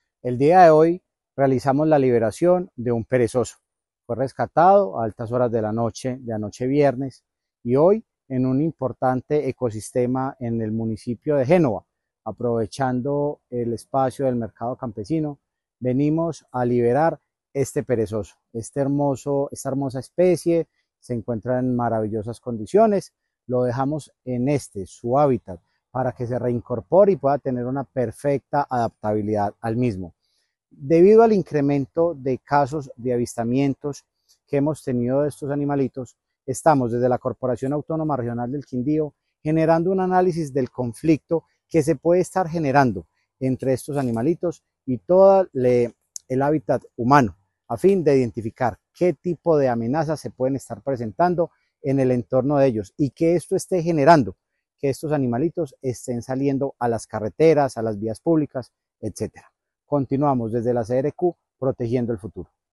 AUDIO JUAN ESTEBAN CORTÉS OROZCO – DIRECTOR GENERAL ENCARGADO DE LA CRQ